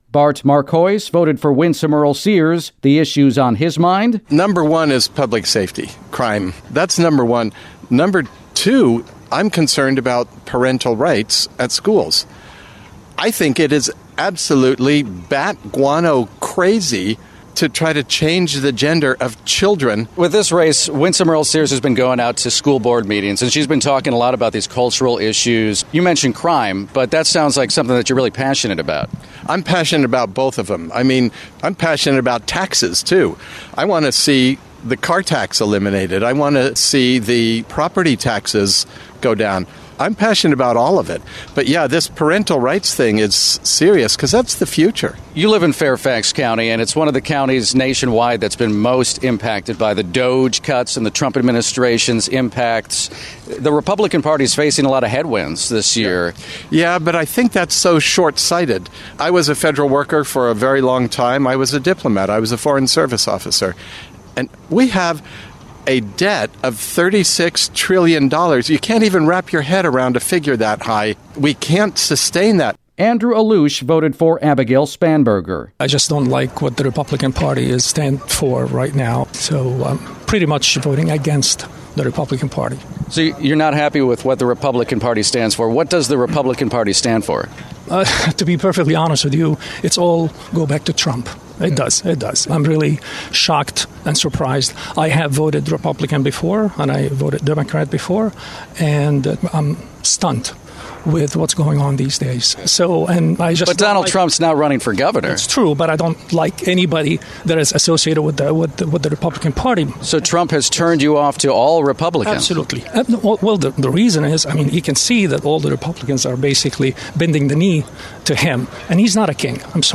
Virginia voters speak out on the topics they're most concerned about.